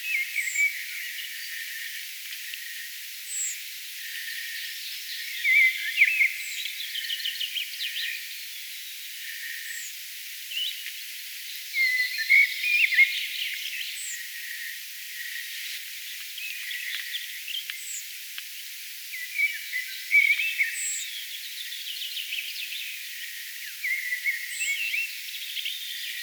keltasirkun huomioääntelyä
Keltasirkkujen tällaista ääntä kuulee nyt.
tietaakseni_keltasirkkuemon_huomioaanta.mp3